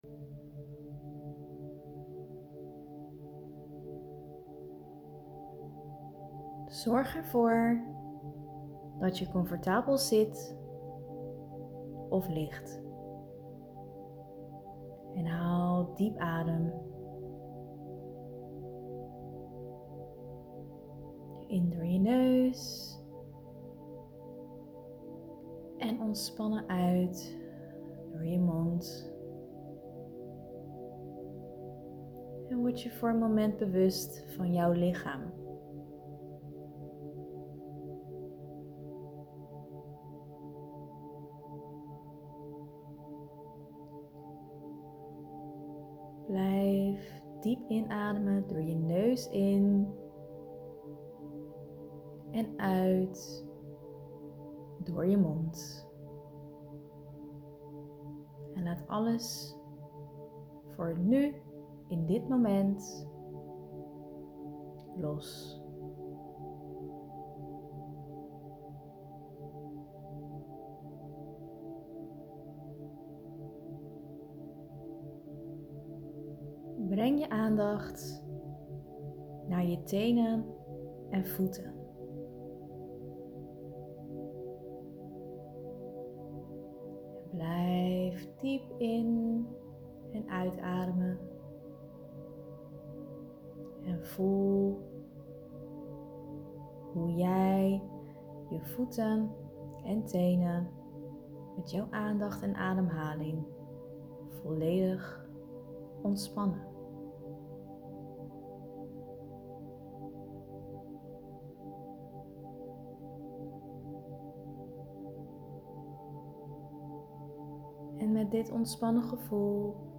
Download dan hiernaast mijn Ademruimte Meditatie.